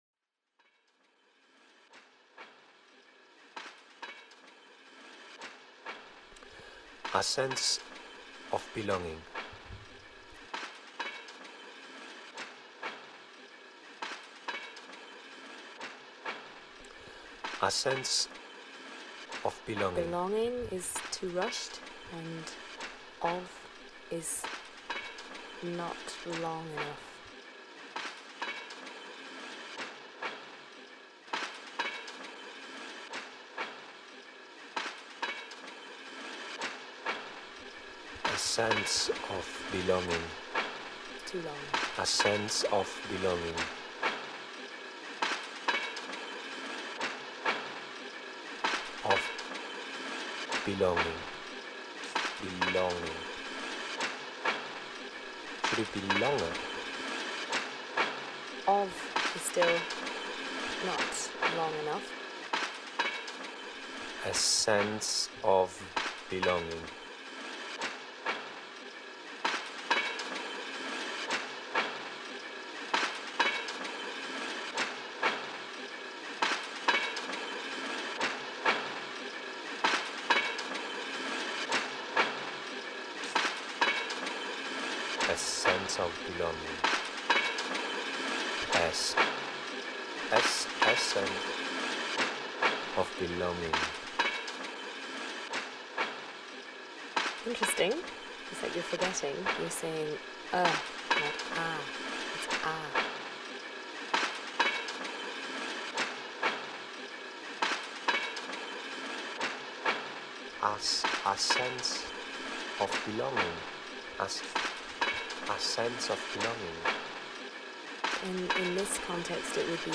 For A Sense of Belonging I hired a professional English teacher to improve my accent. The lesson consists of one single instruction: to repeatedly pronounce the phrase a sense of belonging and be corrected in articulation. The session deliberately dissolves into the absurd: learning becomes a process of destabilisation.